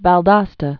(văl-dŏstə)